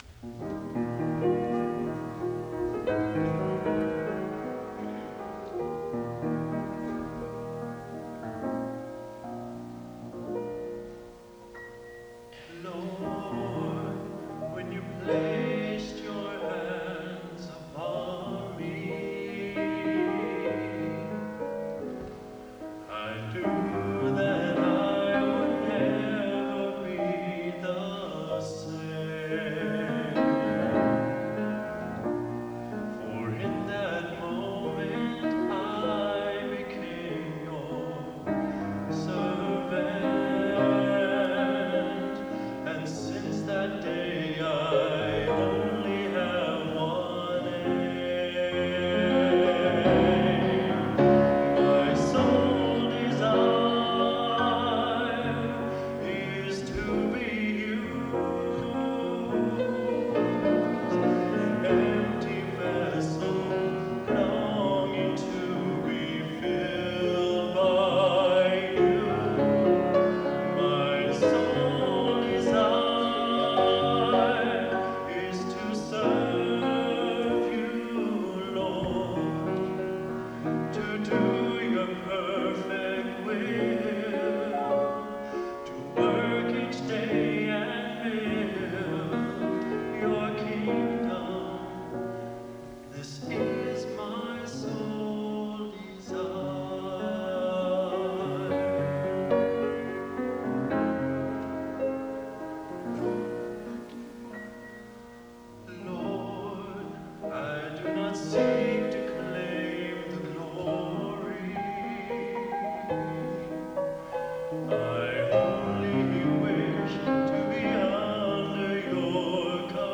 Collection: Broadway Methodist, 1993